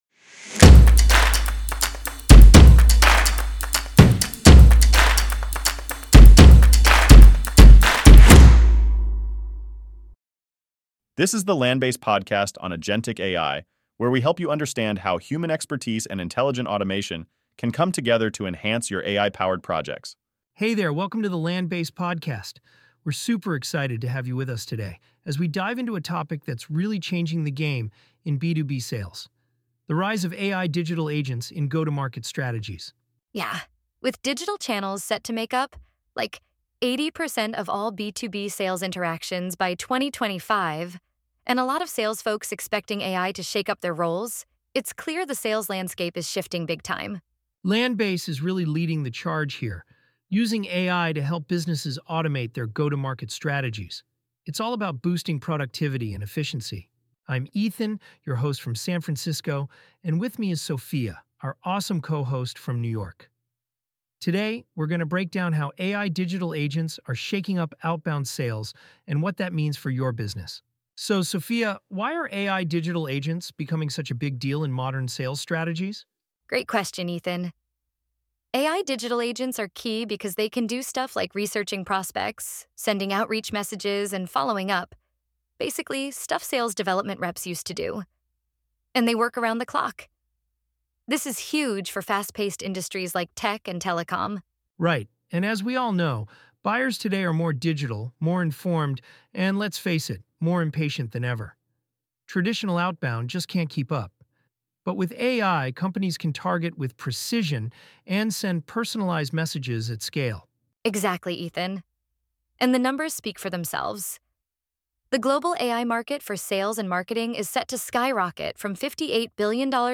Agentic AI in Action dives into how human expertise pairs with intelligent automation to elevate AI-driven projects. Each episode features expert discussions, actionable insights, and strategies to help you harness agentic AI and enhance your go-to-market initiatives.